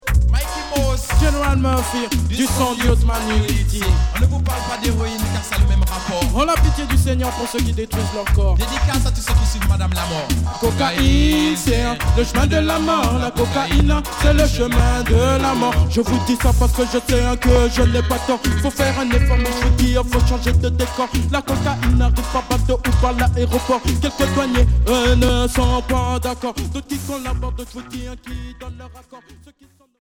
Version dub
Reggae rub a dub